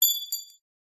Звуки гвоздей, шурупов
Маленький металлический винт или гвоздь упал на бетонную поверхность 5